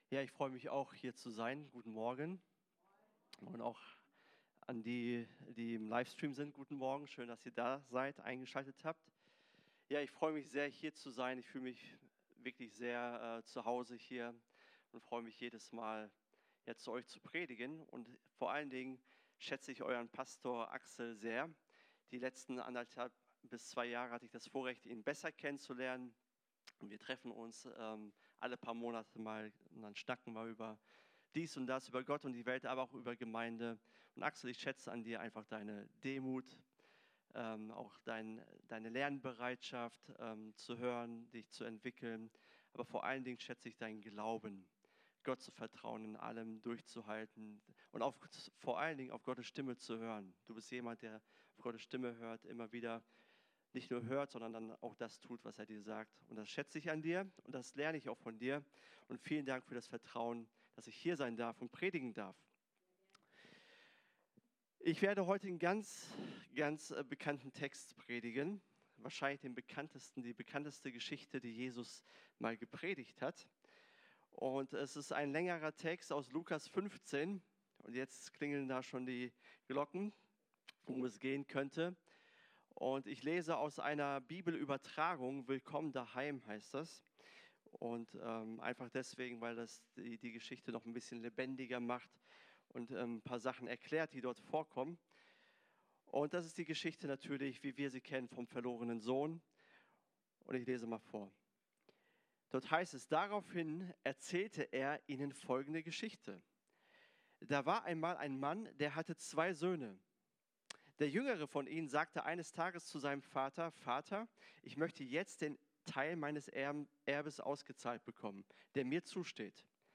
Predigt: „Skandalöse Gnade“